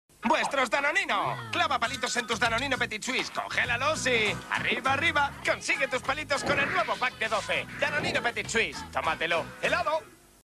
Anuncio de Danonino Petit Suisse sound effects free download